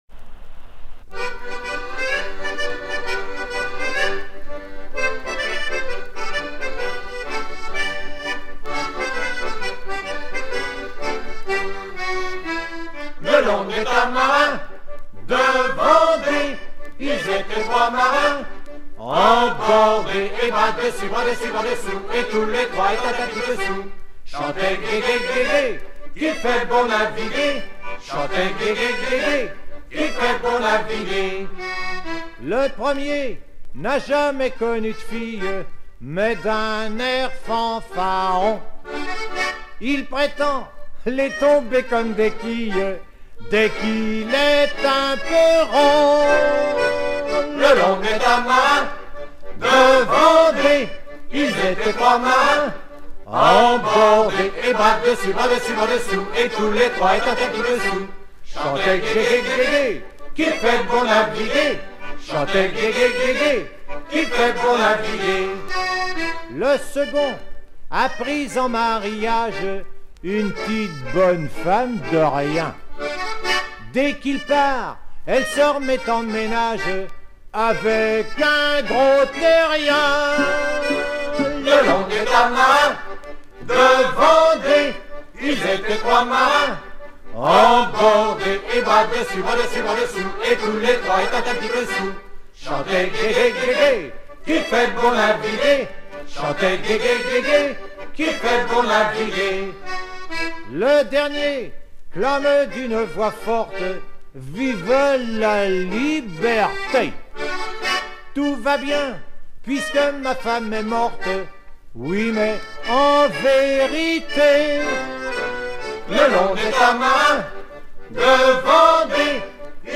Genre strophique
Groupe folklorique
Pièce musicale éditée